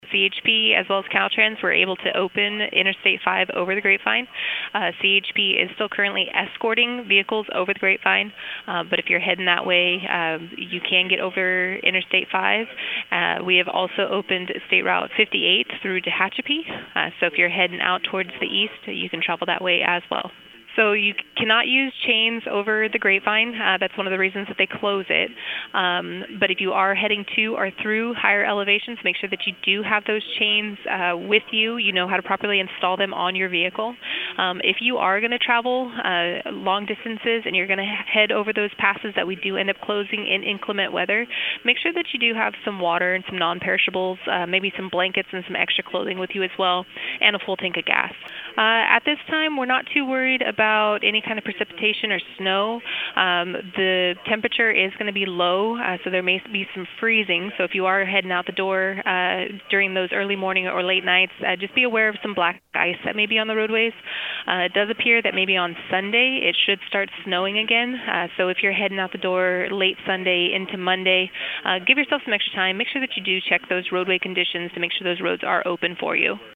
Click to Listen to an update by CHP Officer